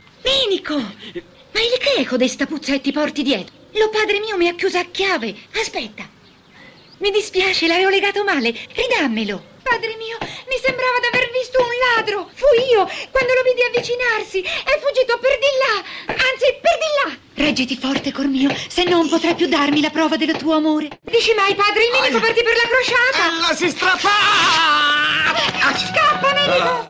nel film "I racconti di Viterburi", in cui � la voce di Tonia.